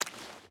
Water Walk 5.ogg